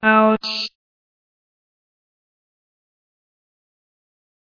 Robot Vox: Ouch (1 second clip)